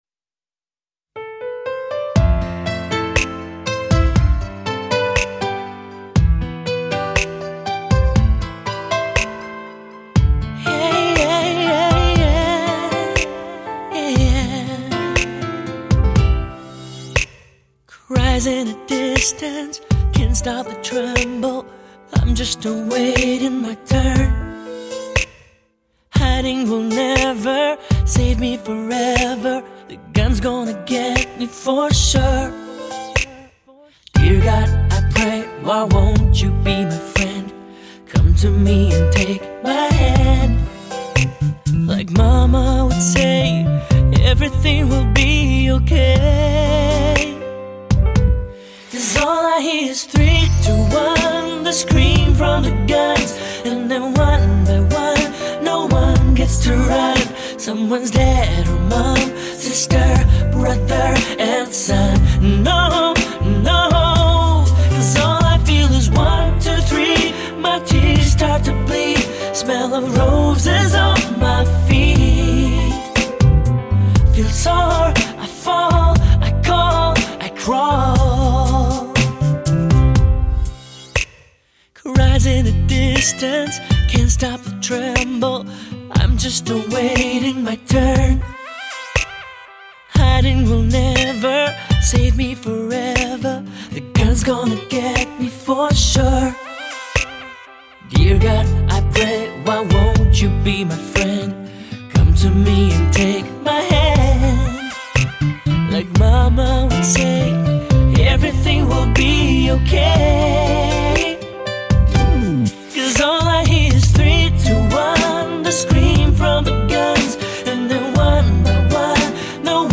2周前 欧美音乐 5